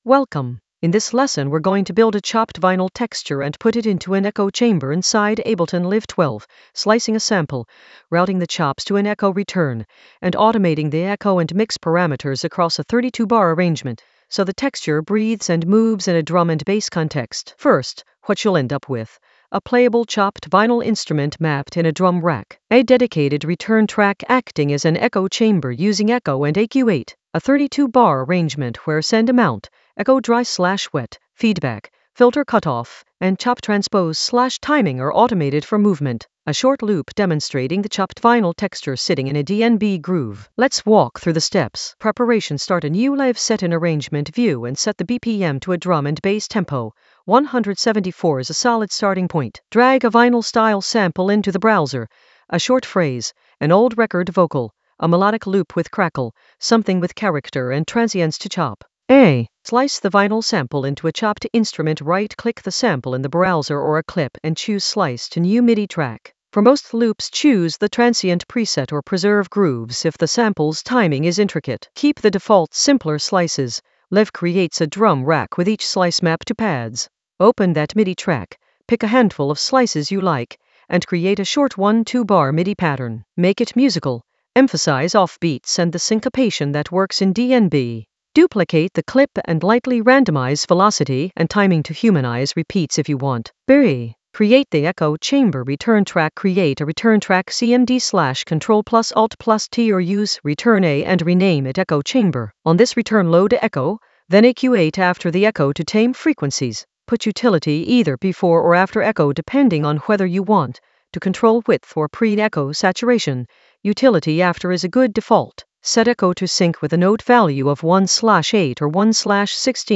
An AI-generated beginner Ableton lesson focused on Echo Chamber a chopped-vinyl texture: design and arrange in Ableton Live 12 in the Automation area of drum and bass production.
Narrated lesson audio
The voice track includes the tutorial plus extra teacher commentary.